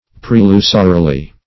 prelusorily - definition of prelusorily - synonyms, pronunciation, spelling from Free Dictionary Search Result for " prelusorily" : The Collaborative International Dictionary of English v.0.48: Prelusorily \Pre*lu"so*ri*ly\, adv.
prelusorily.mp3